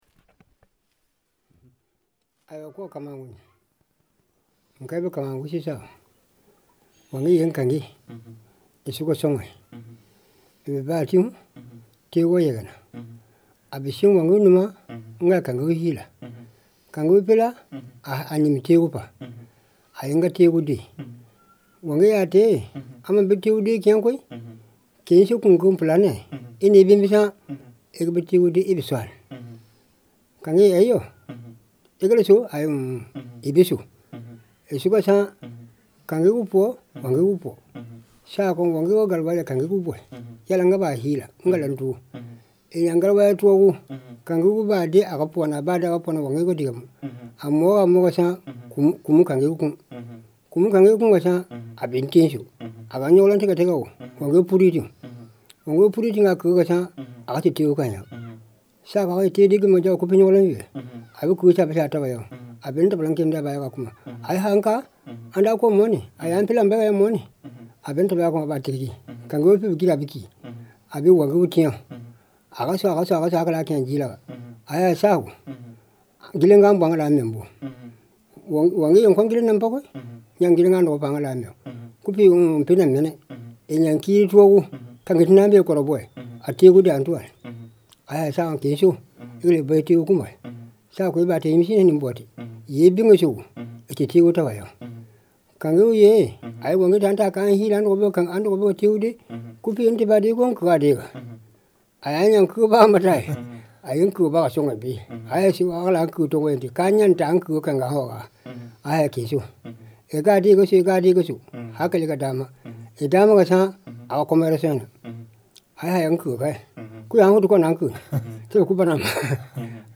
• field recordings in mp3 format made with digital microphone in Namagué village, Mali.